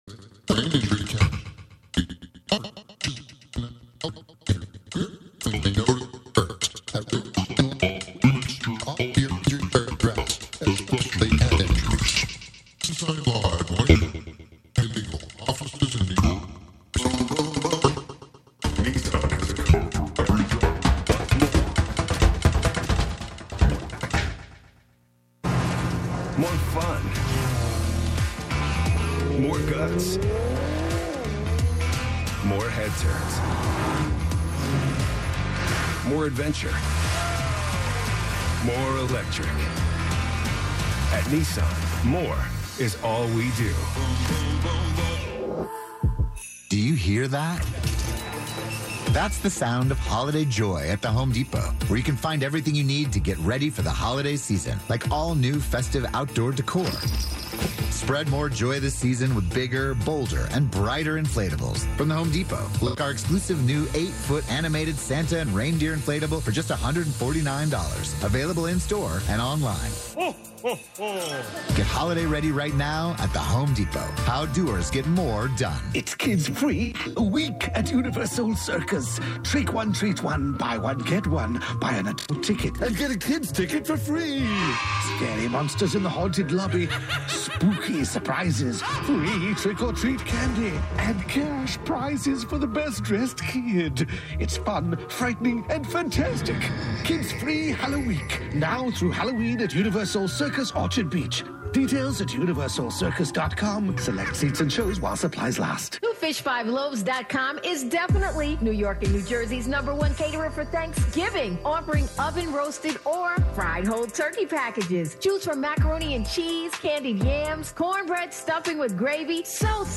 11am Live from Brooklyn, New York
making instant techno 90 percent of the time
play those S's, T's and K's like a drum machine